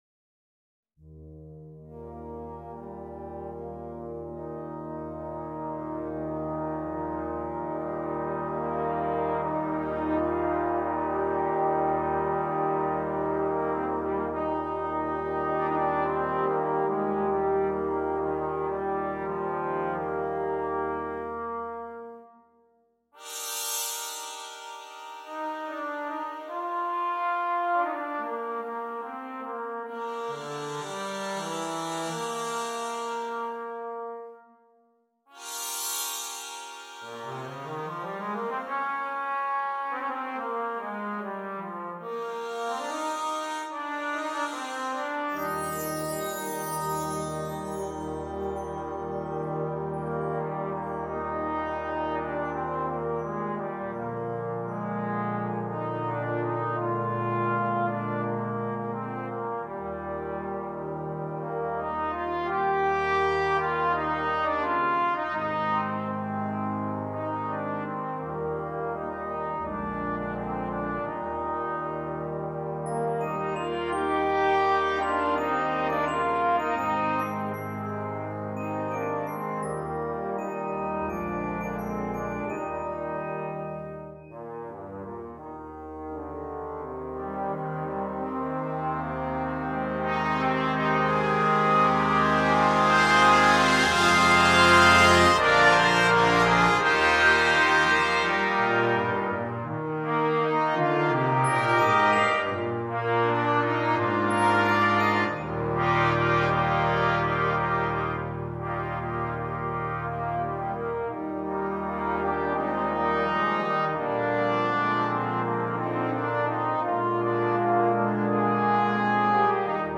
ist ein typisches Beispiel für ein romanisches Heimwehlied.
für Posaunen Solo und Brass Ensemble
Besetzung: Trombone Solo & Ten Piece